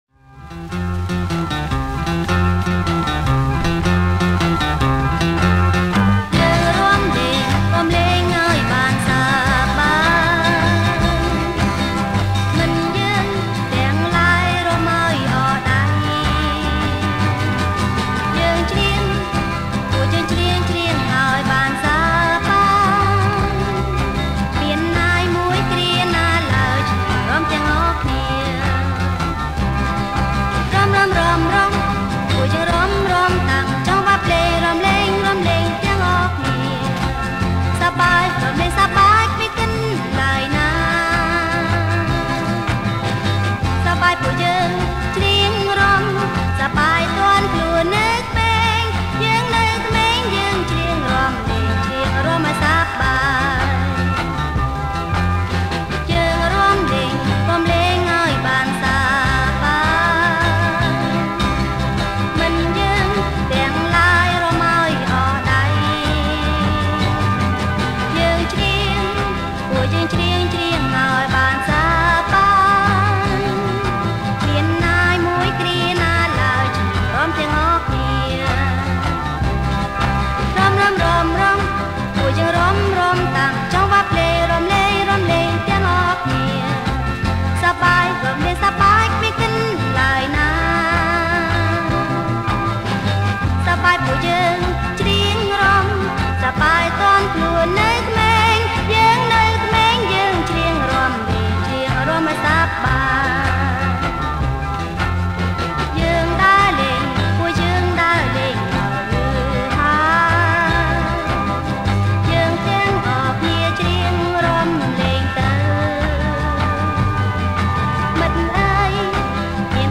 • ប្រគំជាចង្វាក់ Calypso